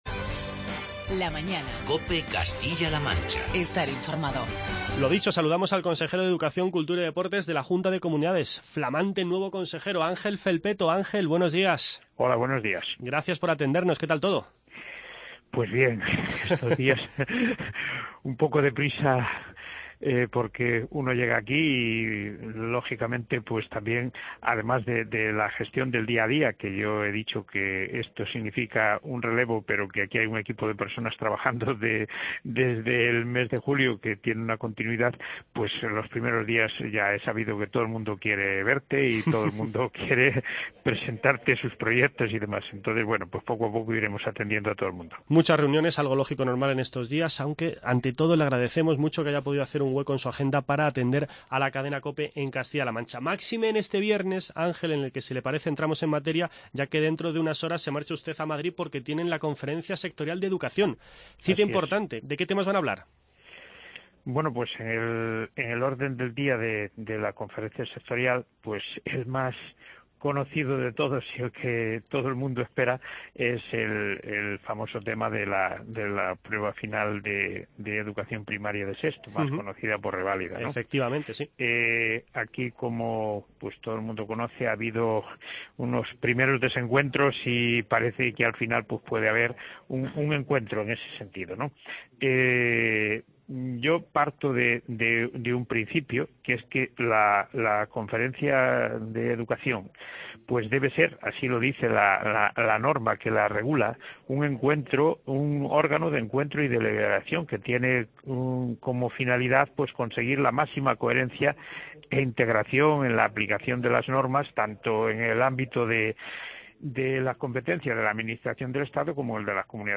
Cita importante hoy en los micrófonos de COPE Castilla-La Mancha con la entrevista con Ángel Felpeto, consejero de Educación, Cultura y Deportes de la Junta de Comunidades. Felpeto se ha referido a la Conferencia Sectorial de Educación que se celebra esta tarde en Madrid y a las oposiciones a maestro que tendrán lugar el próximo 18 de junio. Además, Ángel Felpeto nos ha ofrecido detalles de la noticia anunciada ayer por la Junta por la que el curso que viene se bajarán las ratios a 25 alumnos en los tres cursos de Educación Infantil.